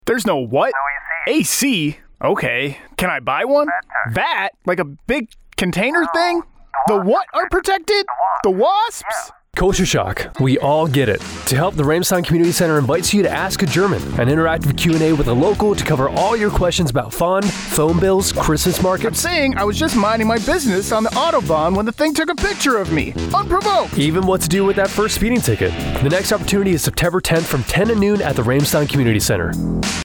Radio Spot - Ask a German